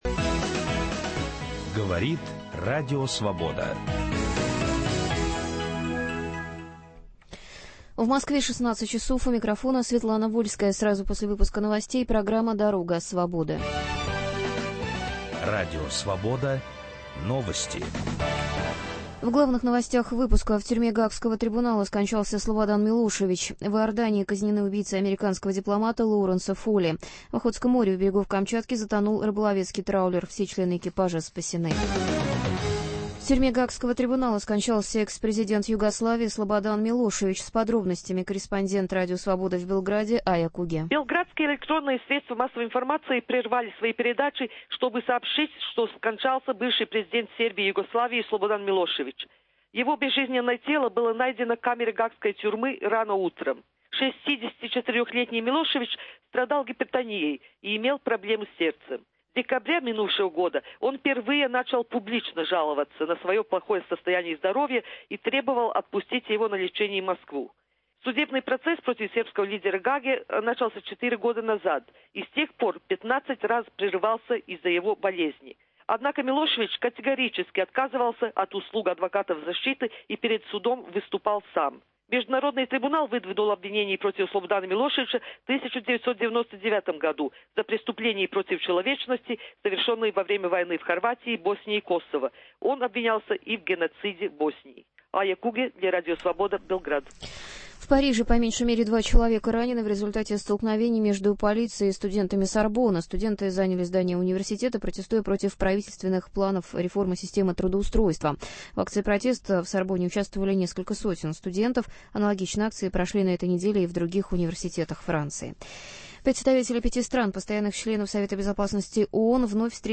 Почему опять поссорились правозащитники? Интервью с Форума "Гражданская восьмерка-2006". "Пермь-36" - репортаж с места уникального лагерного комплекса, сохранившегося с советских времен.